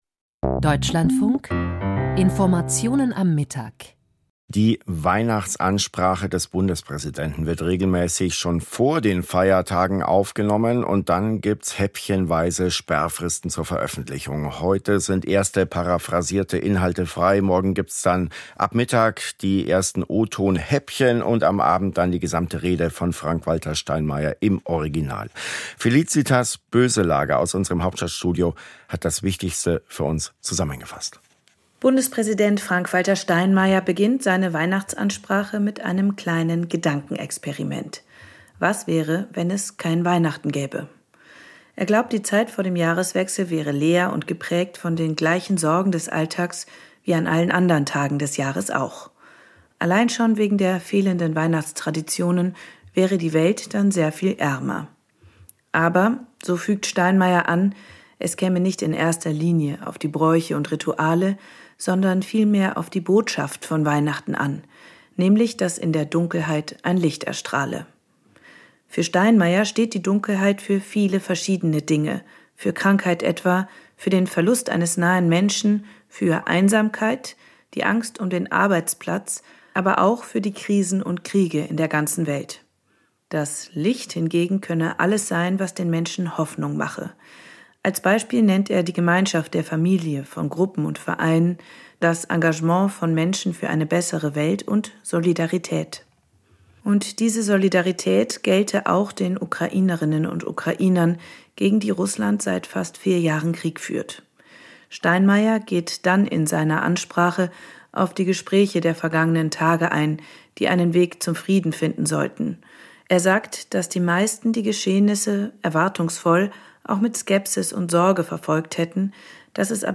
Weihnachtsansprache - Bundespräsident Frank-Walter Steinmeier plädiert für Zuversicht